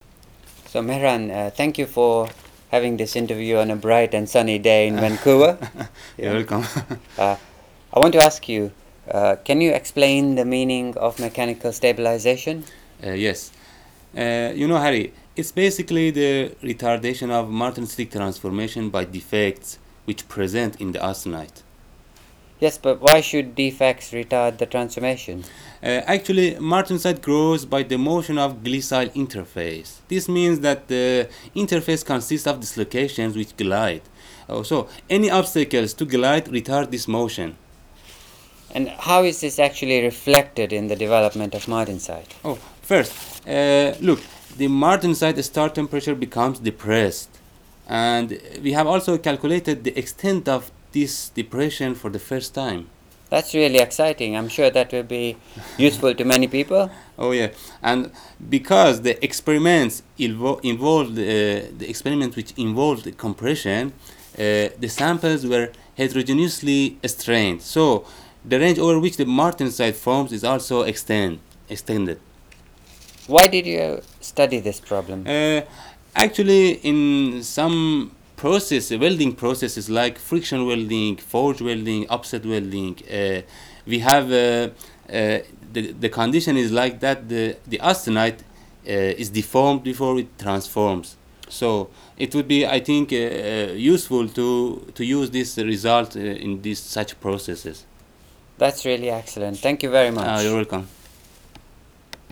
Podcast Short (2 min) interview